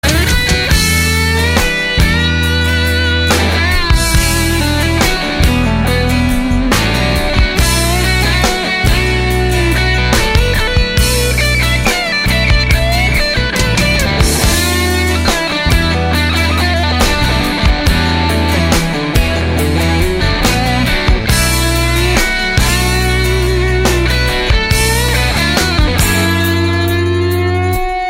• Качество: 128, Stereo
инструментальные